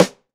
ST DRYRIM1.wav